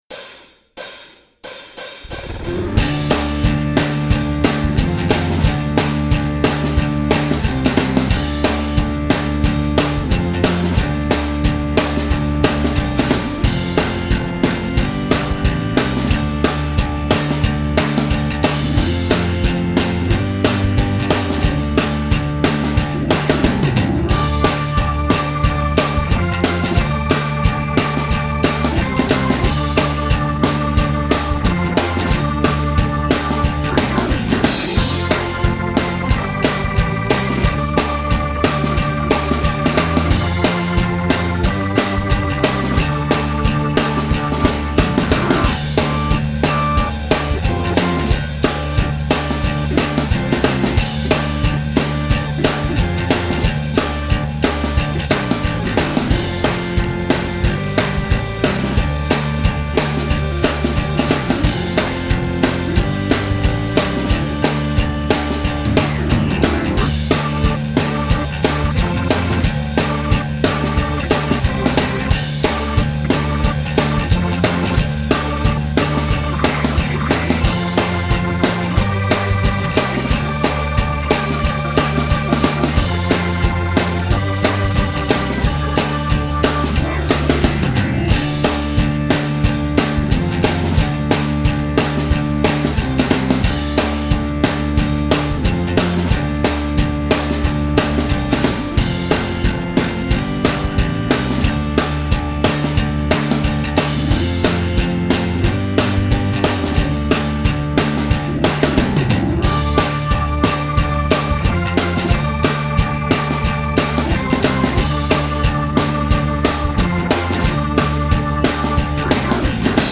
Guitar Lessons: Meat and Potatoes: Standard Rock Licks